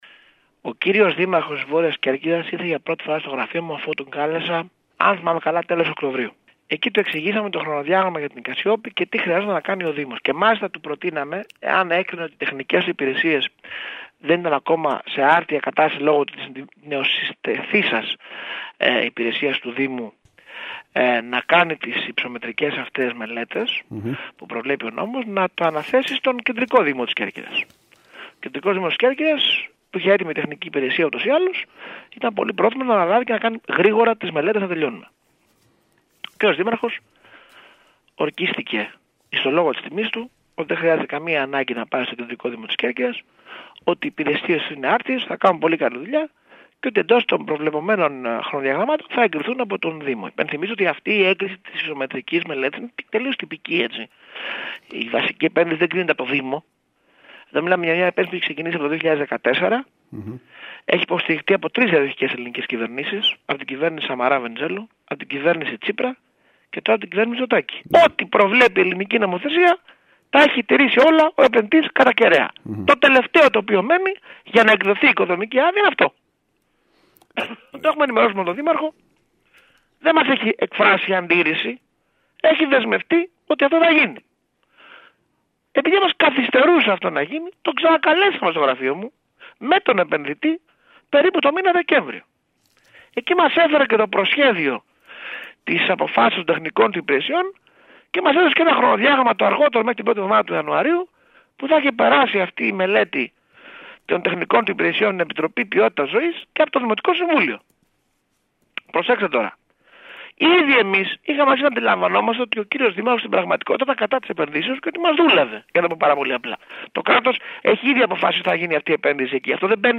Μιλώντας το πρωί στην ΕΡΤ Κέρκυρας, ο Υπουργός Ανάπτυξης Άδωνις Γεωργιάδης κατηγόρησε τον Δήμαρχο Βόρειας Κέρκυρας για παλινδρομήσεις, αναφέροντας ότι είχε δεσμευθεί ενώπιον του ιδίου και των επενδυτών τον Οκτώβριο και τον Δεκέμβριο ότι η έγκριση των υψομετρικών θα δοθεί εντός του Ιανουαρίου.